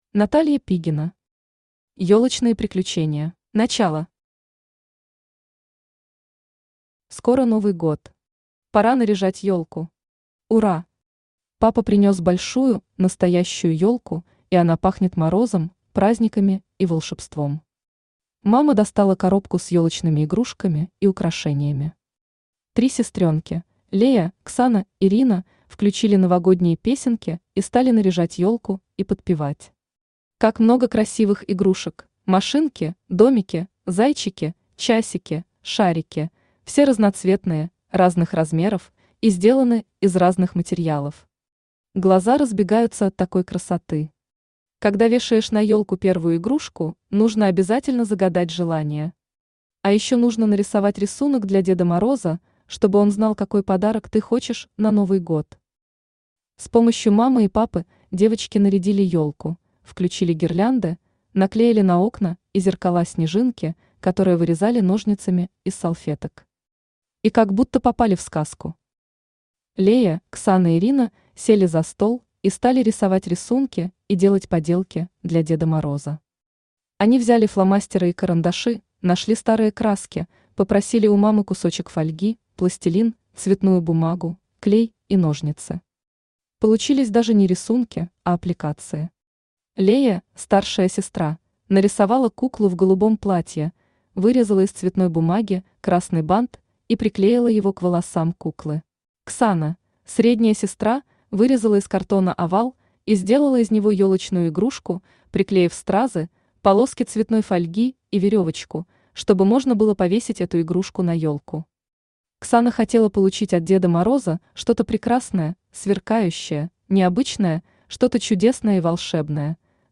Аудиокнига Ёлочные приключения | Библиотека аудиокниг
Aудиокнига Ёлочные приключения Автор Наталья Алексеевна Пигина Читает аудиокнигу Авточтец ЛитРес.